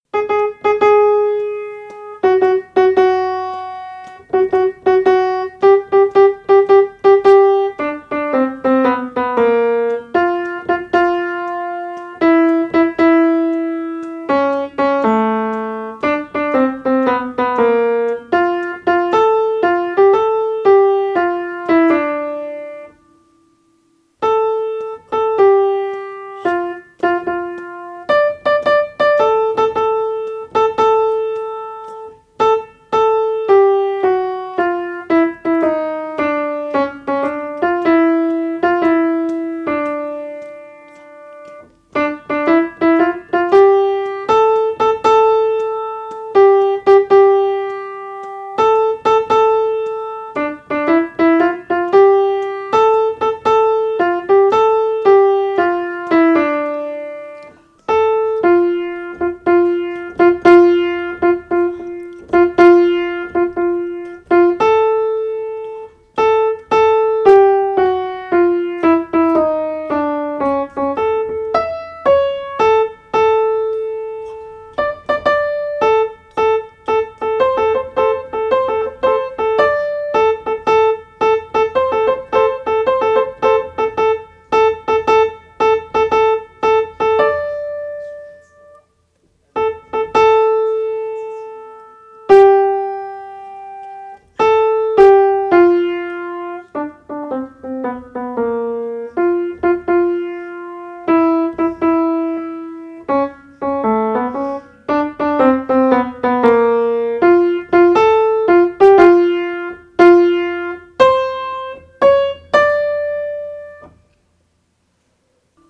Joshua, Jéricho alto